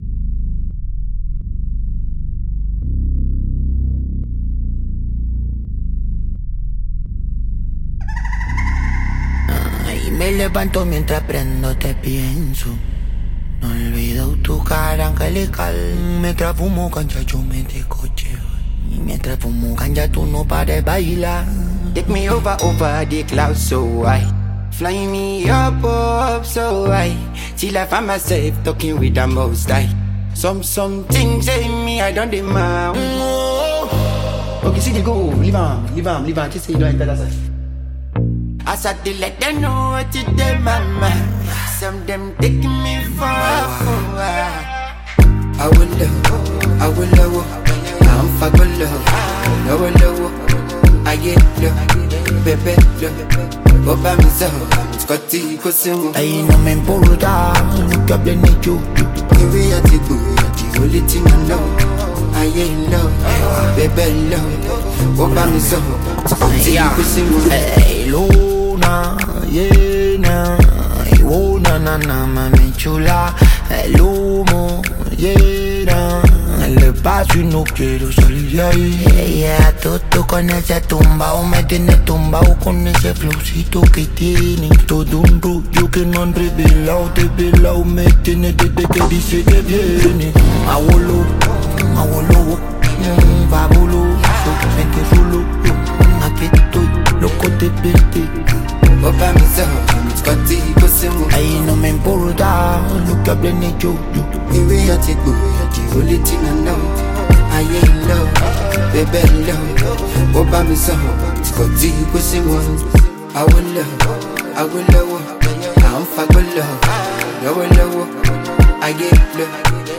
high-tempo track